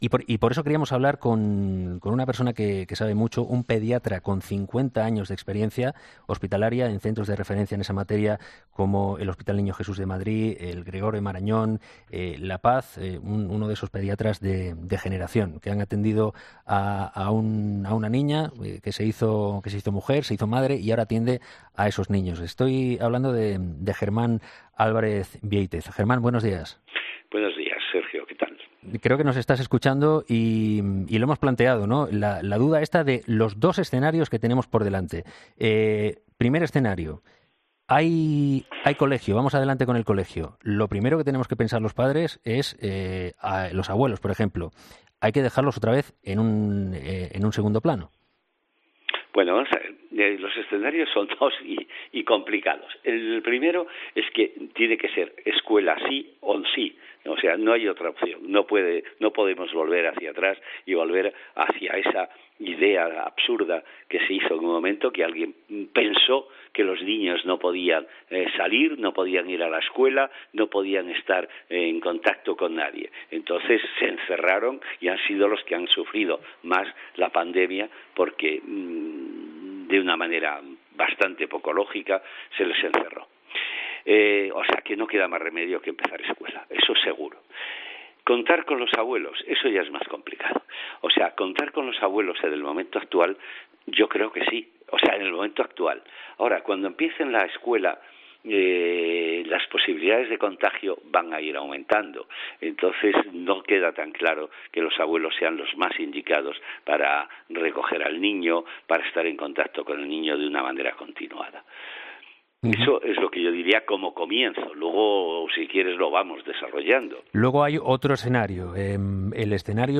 Un pediatra, sobre la vuelta al colegio: "Escuela sí o sí, no podemos volver hacia atrás"